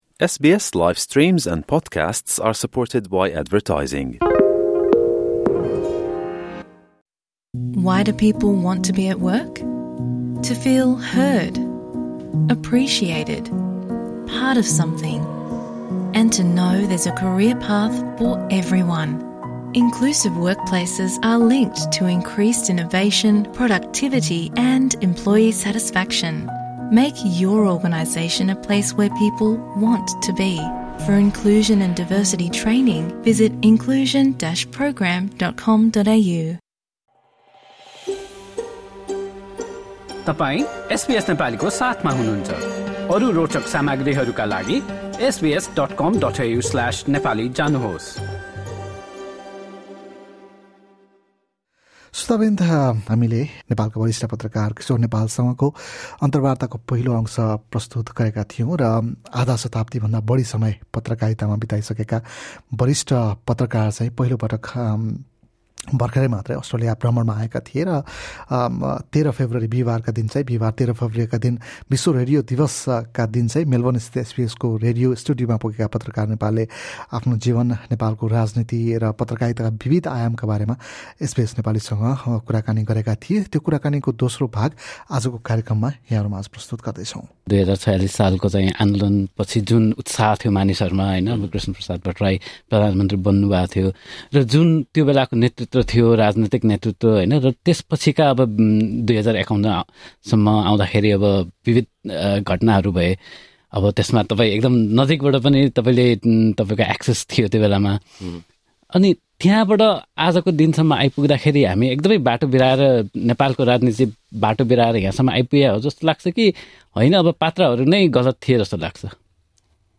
संवाद
मेलबर्नस्थित एसबीएसको रेडियो स्टुडियो